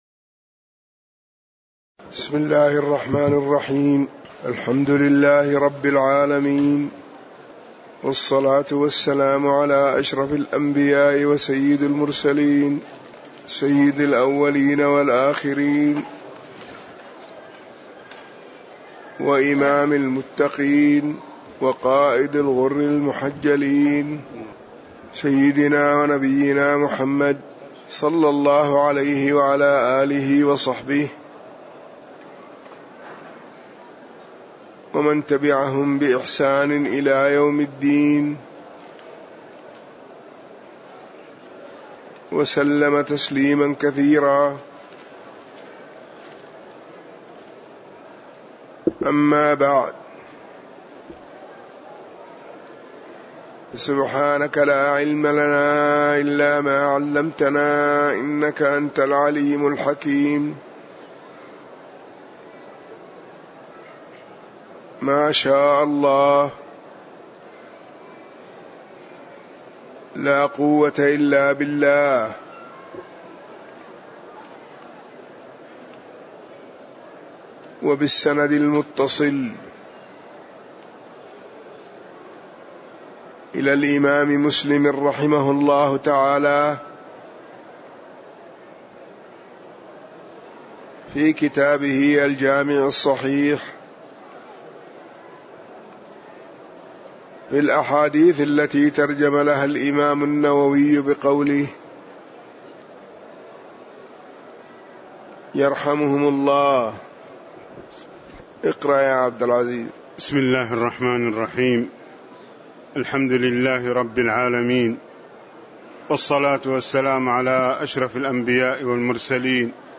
تاريخ النشر ١٦ صفر ١٤٣٨ هـ المكان: المسجد النبوي الشيخ